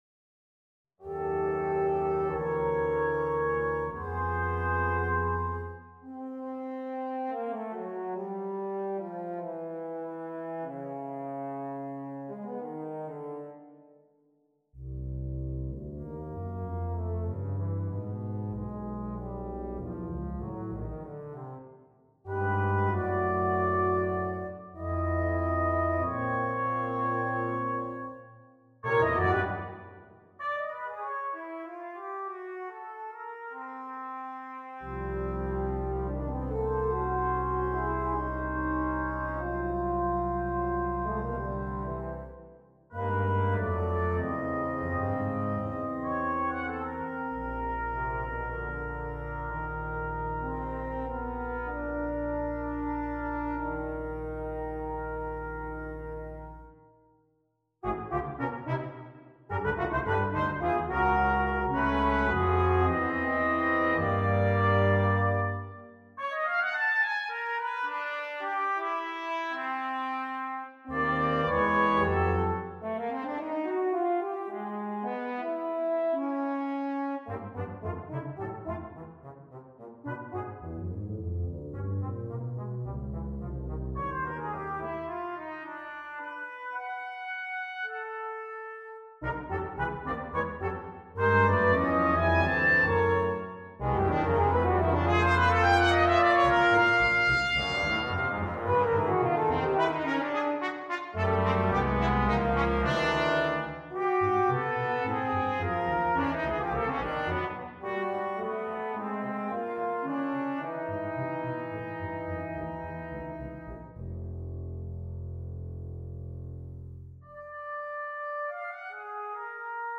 for Brass Quartet
on a purpose-selected tone row
Andante espansivo - Allegretto con moto - Tempo Primo - Allegro ma non troppo - Più mosso - Tempo Terzo - Tempo Secondo - Adagio